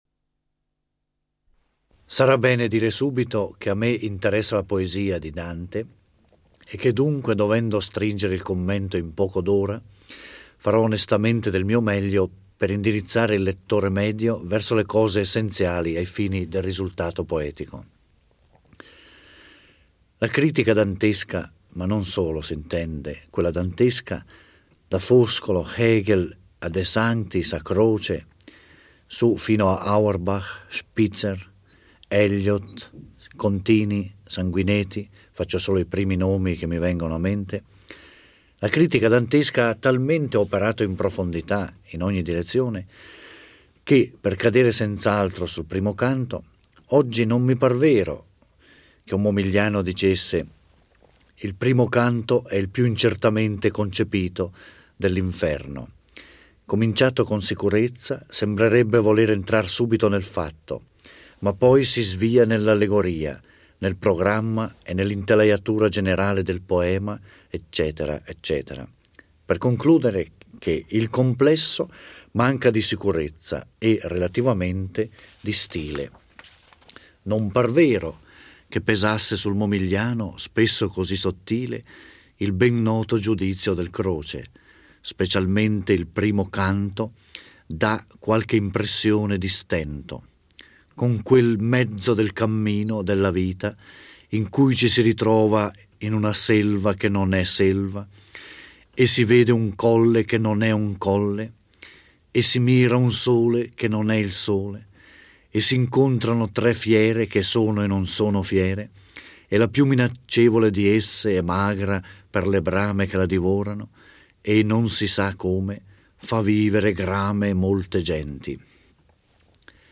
Giorgio Orelli legge e commenta il I canto dell'Inferno. La "Divina Commedia" si apre con la descrizione allegorica di una selva oscura.